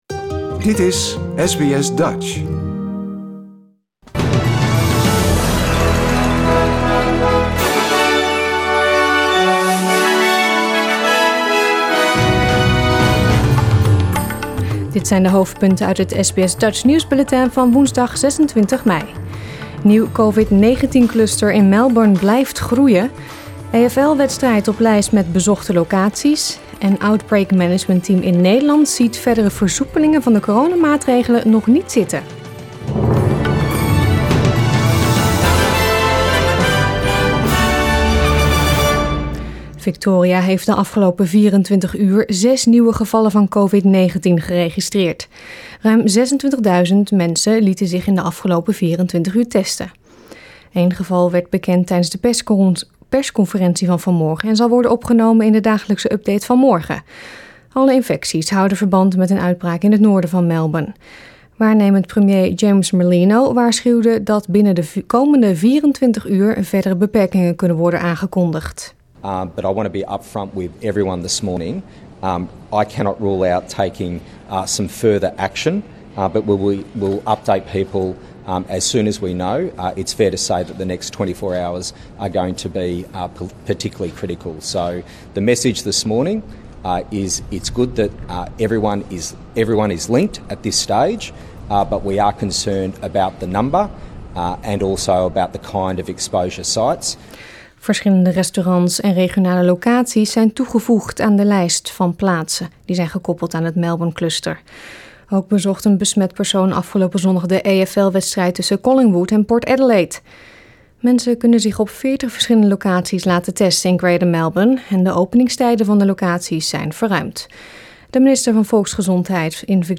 Nederlands/Australisch SBS Dutch nieuwsbulletin van woensdag 26 mei 2021